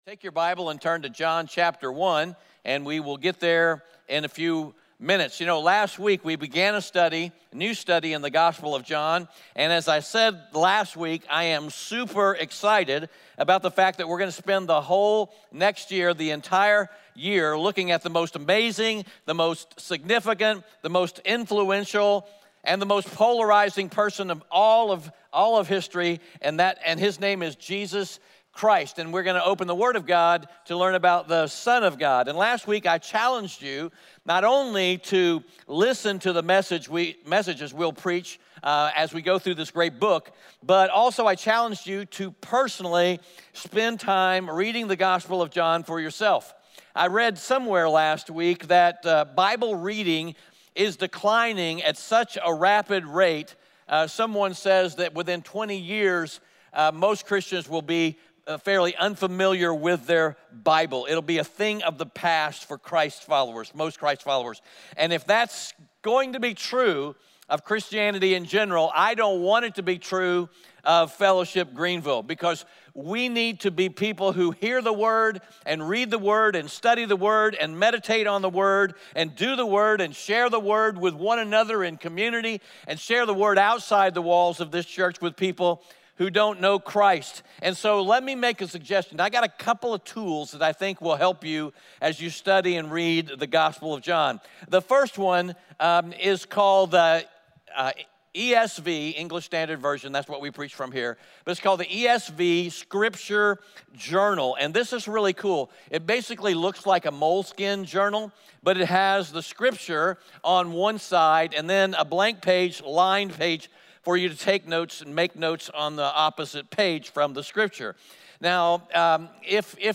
John 1:1-18 Audio Sermon Notes (PDF) Ask a Question Scripture: John 1:1-18 Just curious…did you create space in your life this past week to read through the Gospel of John?